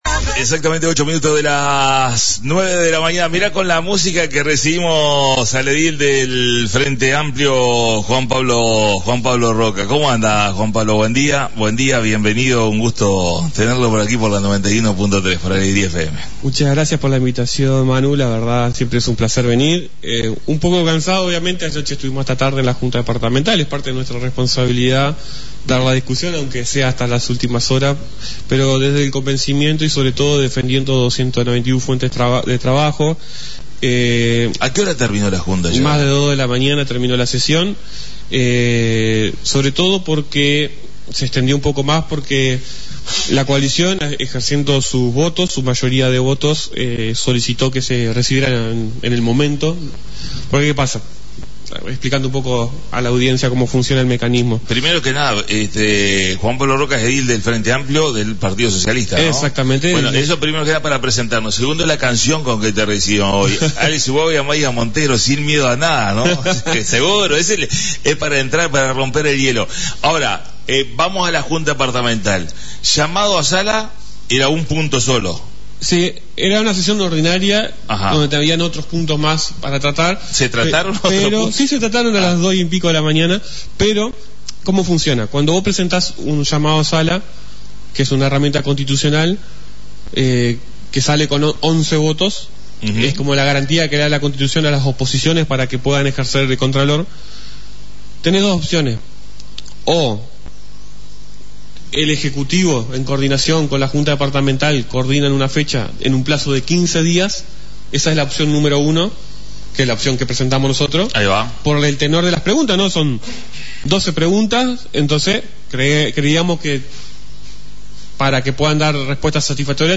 La visita a la Radio del Edil del Partido Socialista Frente Amplio Juan Pablo Rocca